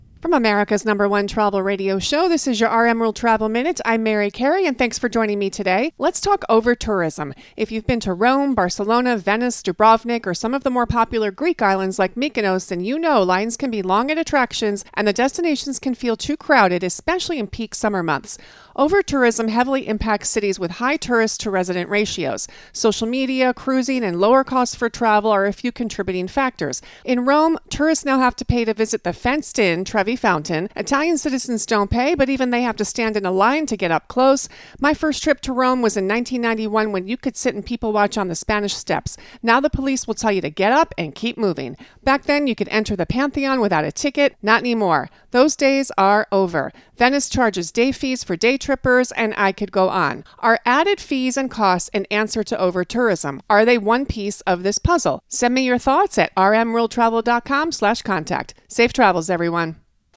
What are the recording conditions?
America's #1 Travel Radio Show